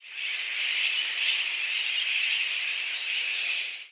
Tennessee Warbler diurnal flight calls
Sooty Terns calling in the background.